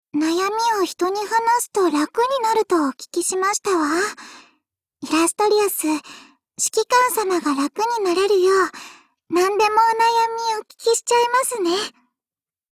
碧蓝航线:小光辉语音